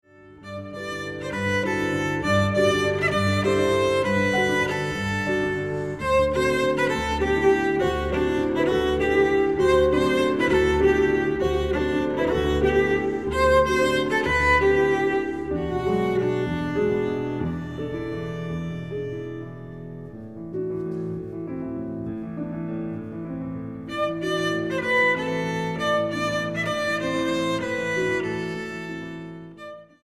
violoncello
piano